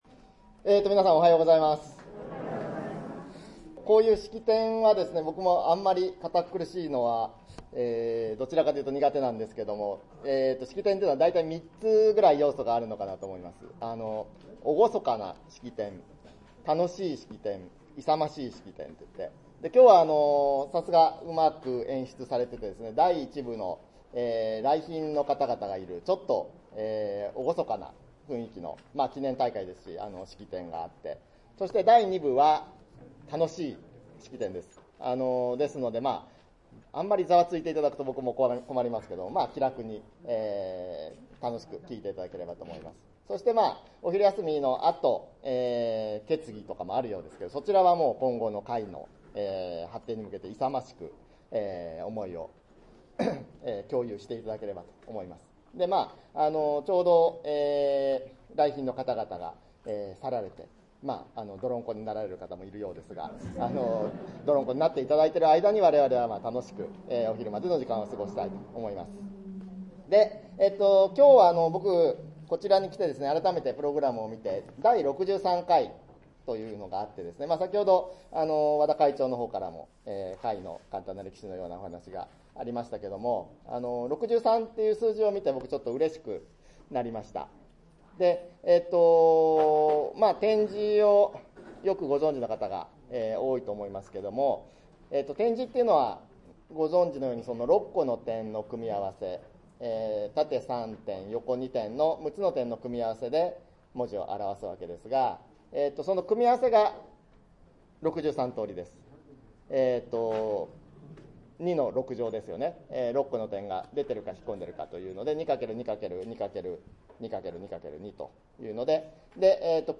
講演:第63回愛媛県視覚障害者福祉大会(平成24年度) / 公益財団法人 愛媛県視覚障害者協会
第63回福祉大会 講演